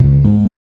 2008R BASS.wav